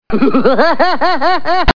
Krusty Laugh